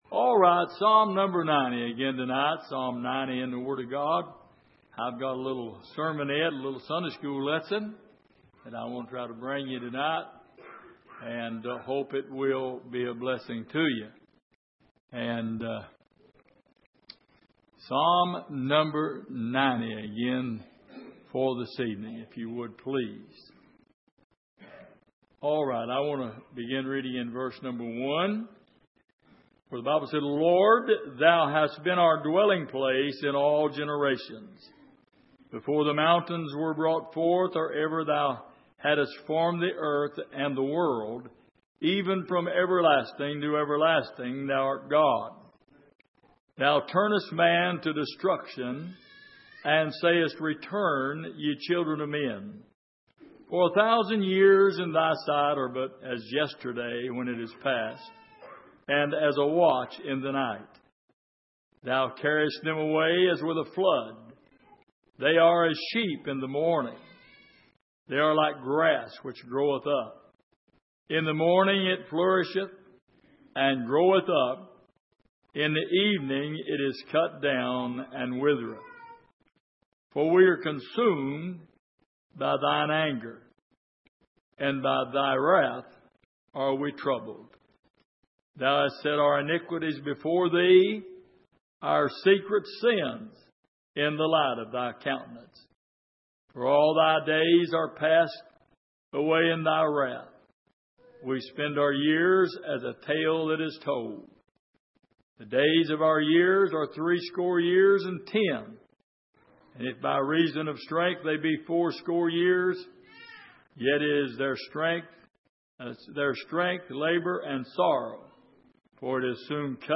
Passage: Psalm 90:1-11 Service: Midweek